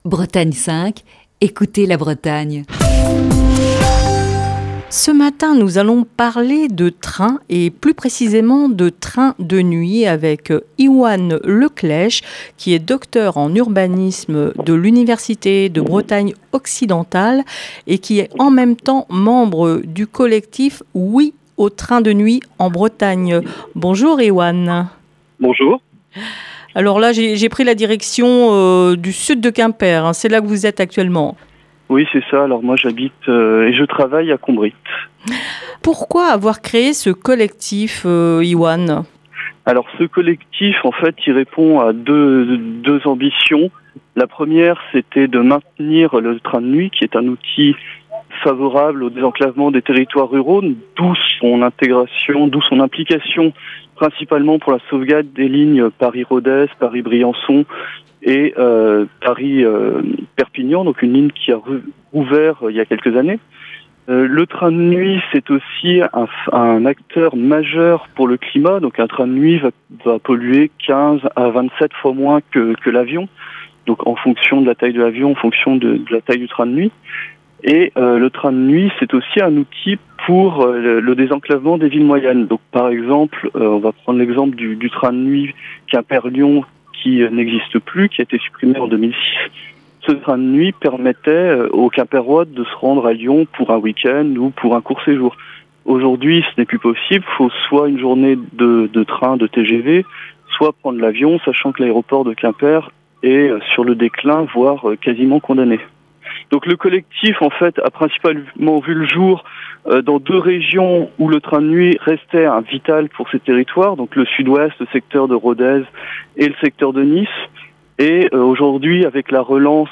Émission du 9 septembre 2020.